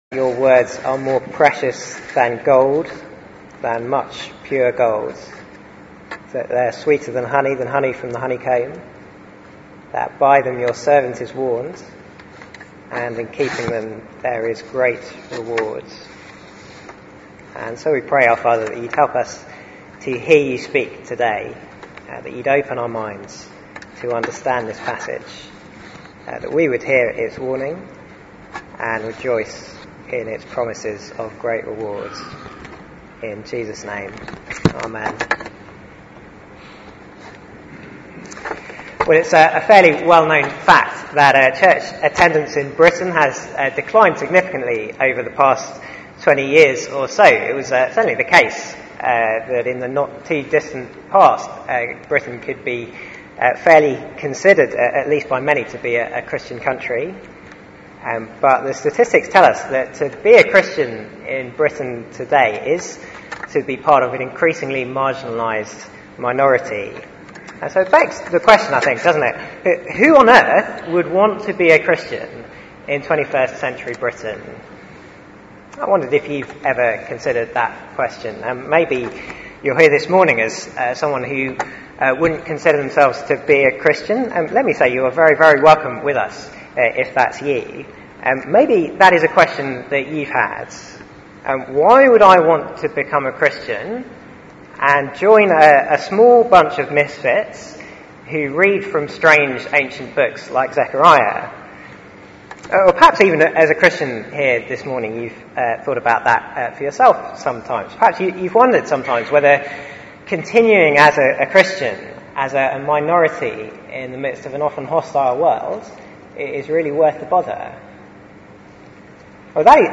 The man among the myrtle trees Sermon